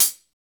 CYM X14 HA06.wav